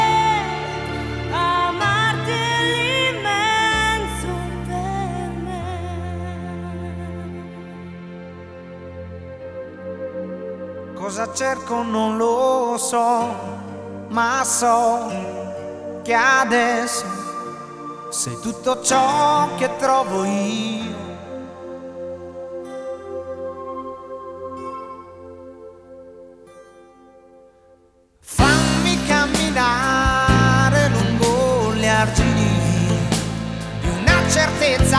Рингтоны
Жанр: Поп